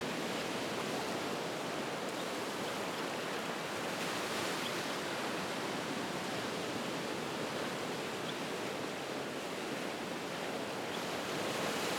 Purple Sandpiper
Listen – To the purple sandpipers squabbling amongst each other.  Their high pitch calls can just be heard above the crashing waves.
Purple-Sandpipers-squabbling-trimmed.m4a